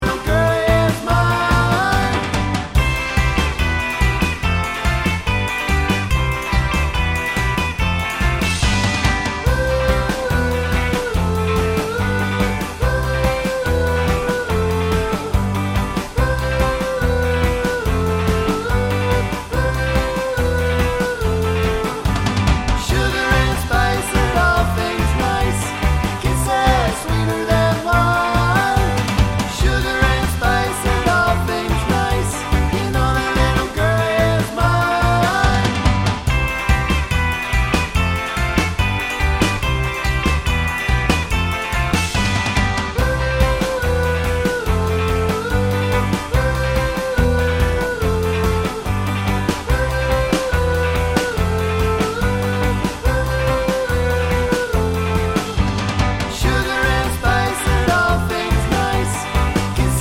2025 Remake Pop (1960s) 2:15 Buy £1.50